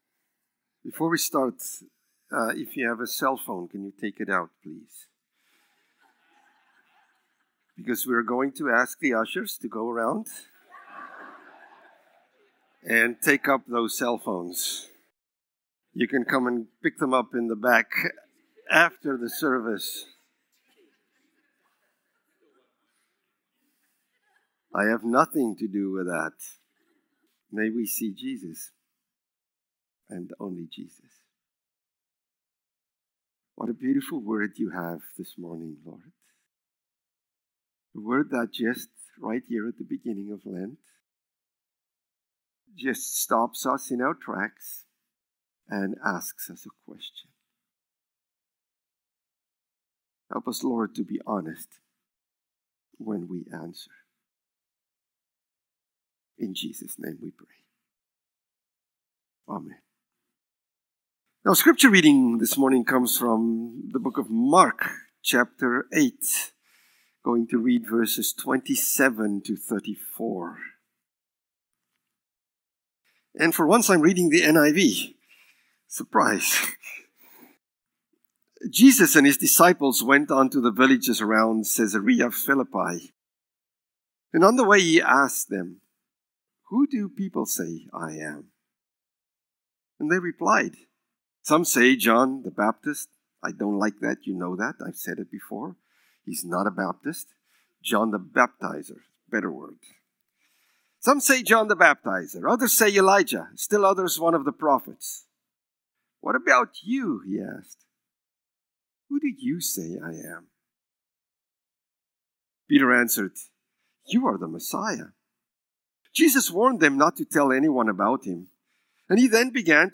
February-22-Sermon.mp3